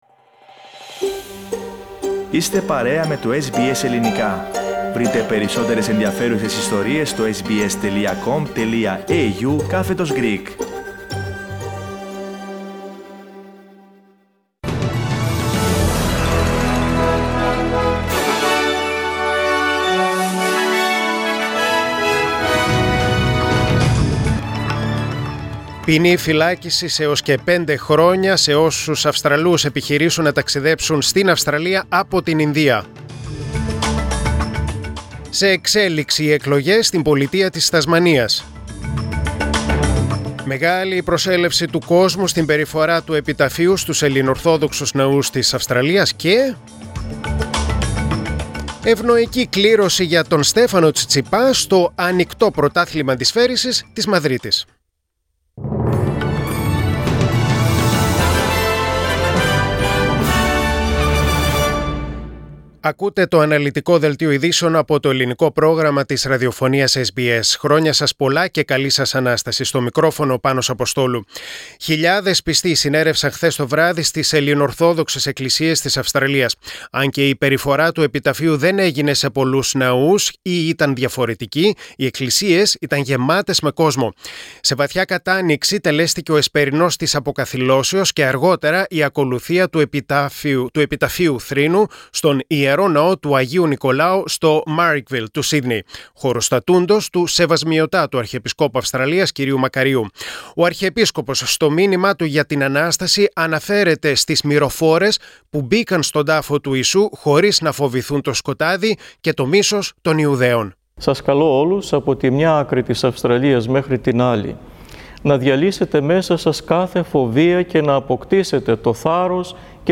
News in Greek: Saturday 01.05.2021